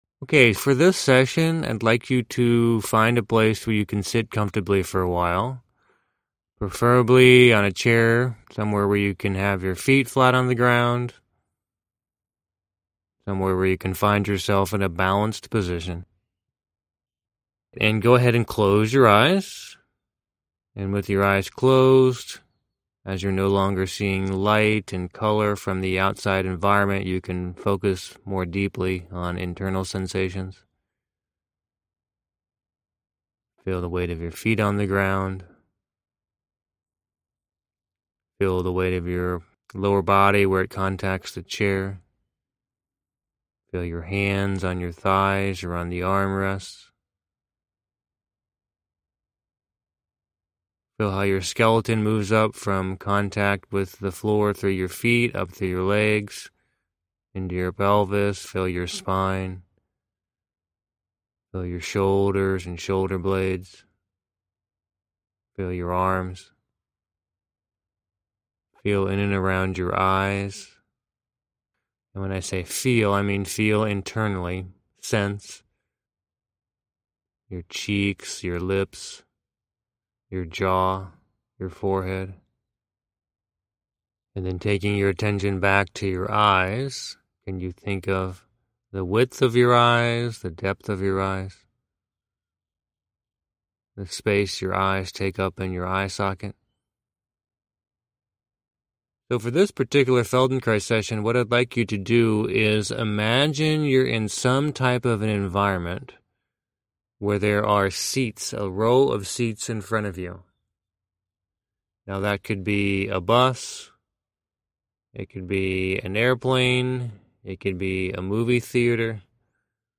Imaginary Ball In Front Of The Eyes (Free Feldenkrais Session) - Emergent Somatics
Though my original session is about 17-minutes, I edited this down for you to about 13-minutes for ease of use.